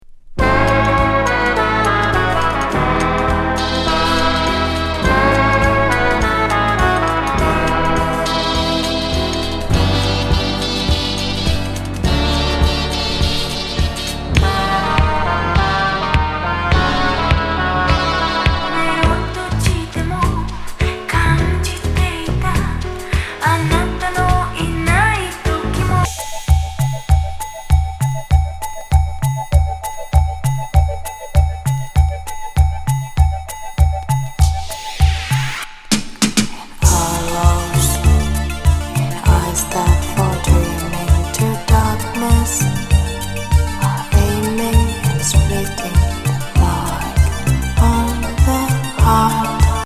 ロマンチック・ディスコティーク